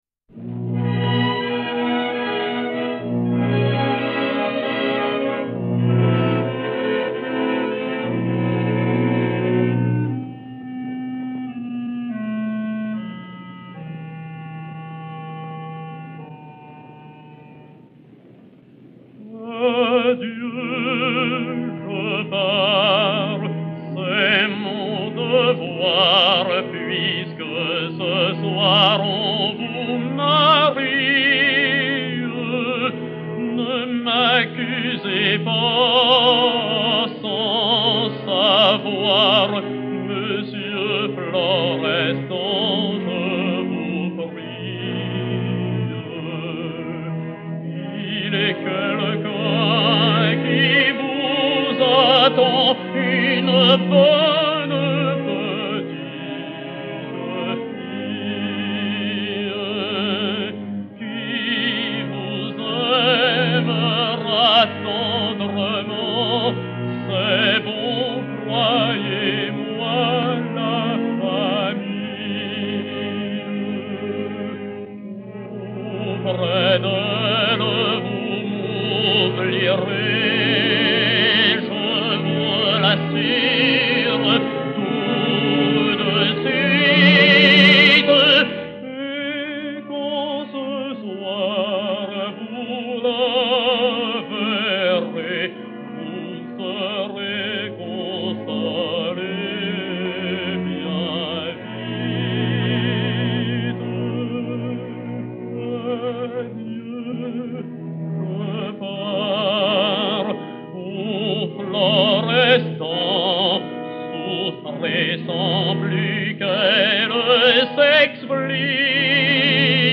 baryton français